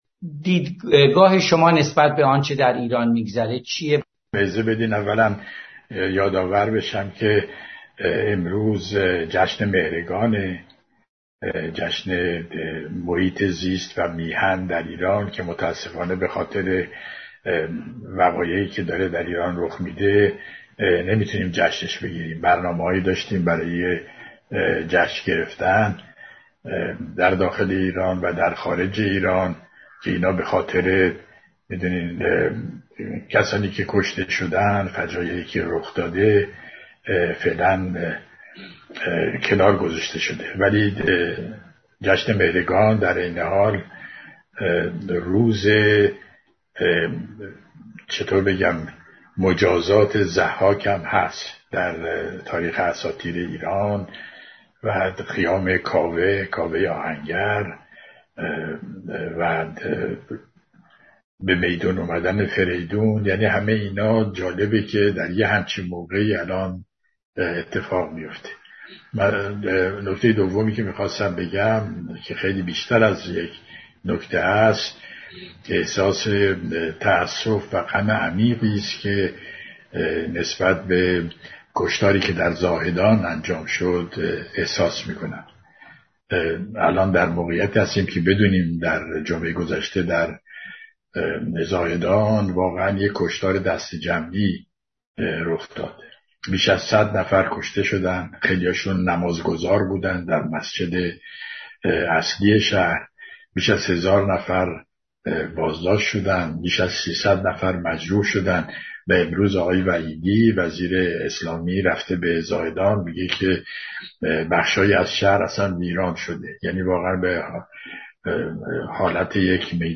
گلچین دیگری از گفت و گوهای دکتر امیر طاهری